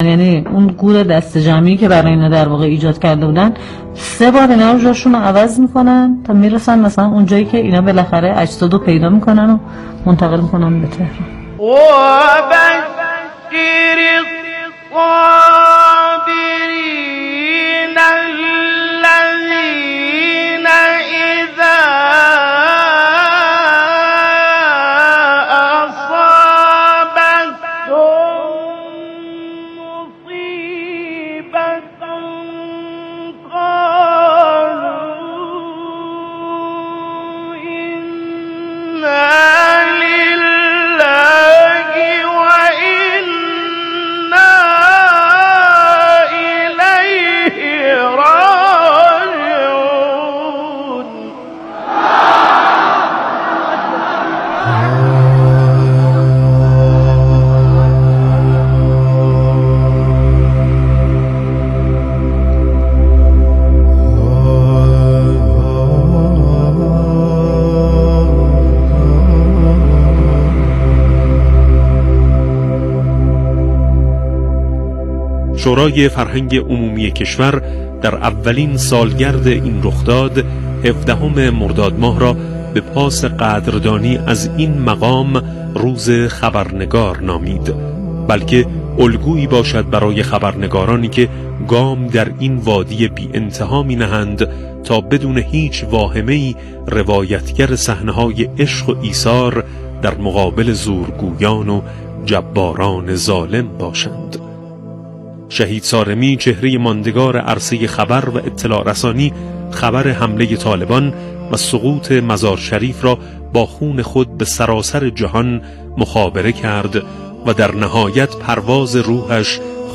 به گزارش ایکنا، برنامه «حکایت جاودانگی» رادیو قرآن در آستانه روز خبرنگار، امروز، 14 مردادماه با نگاهی به زندگینامه شهید «محمود صارمی» از شهدای خبرنگار به روی آنتن این شبکه رادیویی رفت.